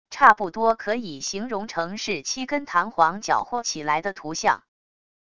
差不多可以形容成是7根弹簧搅和起来的图像wav音频